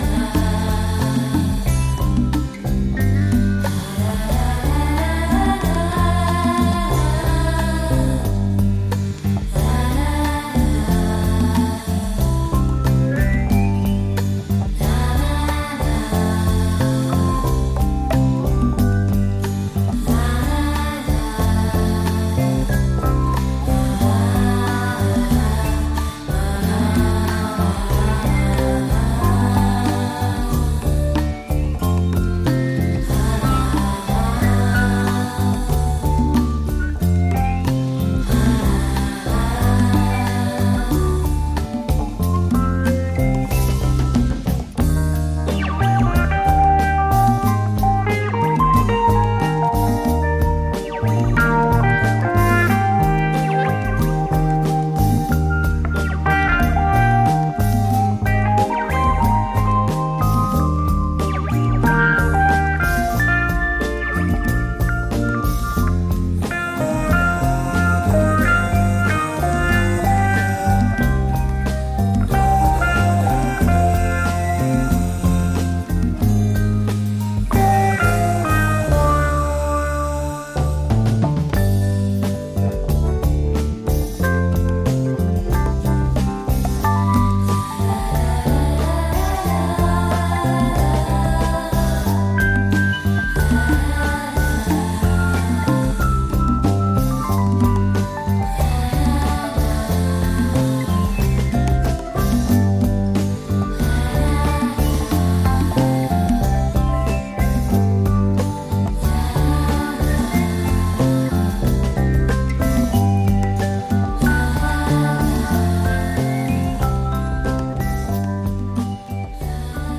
unadulterated Italian cinematic trip-hop
the sensual vocals
the phenomenal percussion
clavinet and fender rhodes